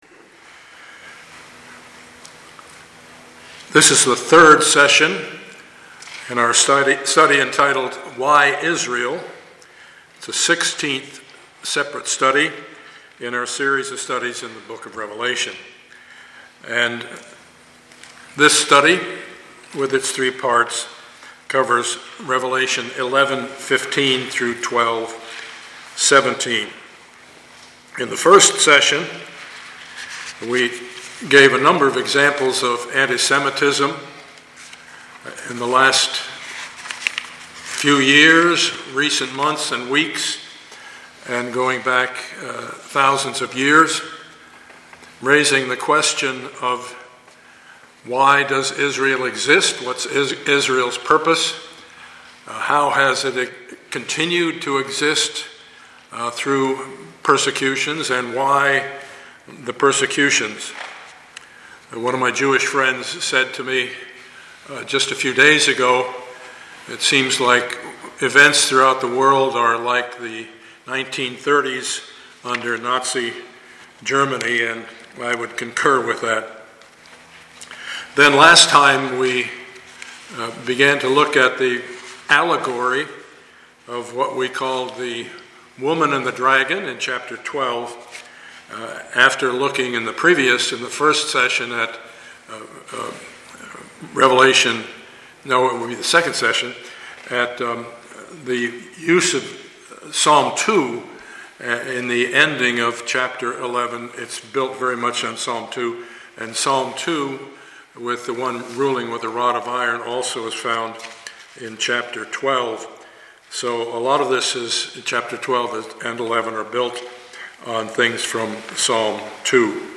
Revelation 11:15-12:17 Service Type: Sunday morning « Studies in the Book of Revelation #16B